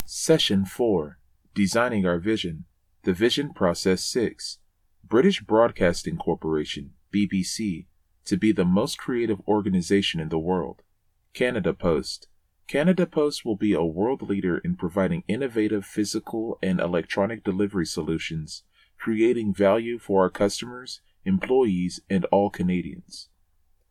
Voice Over Work